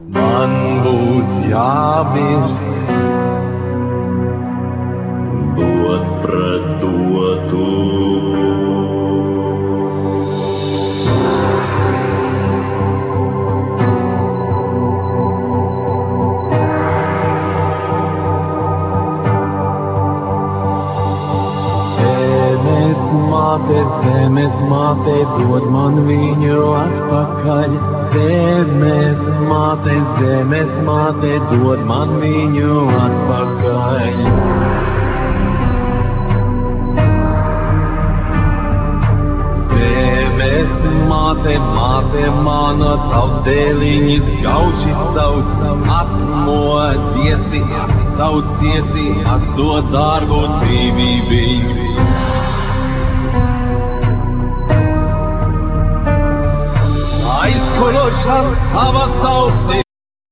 VocalViolin
Vocal,Kokle(box zither),Bagpipes,acordion,Keyboards